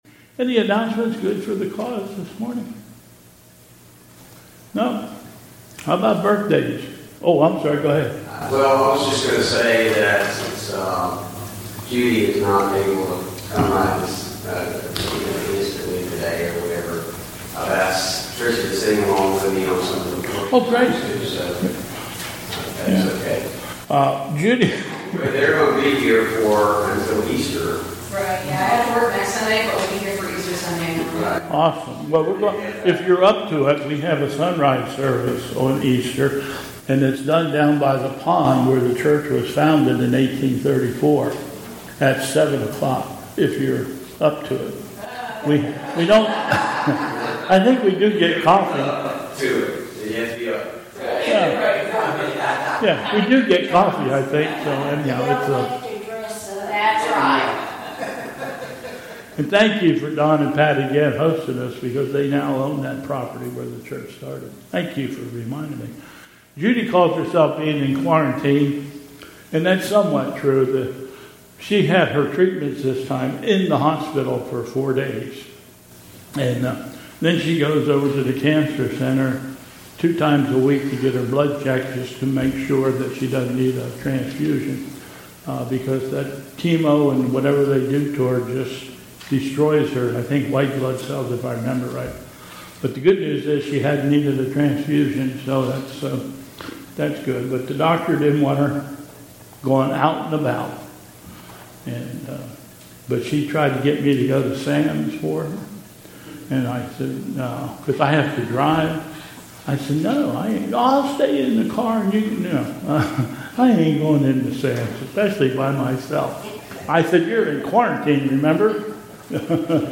Bethel Church Service
Continuing with the Announcements...